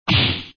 13_hard_punch.wav.mp3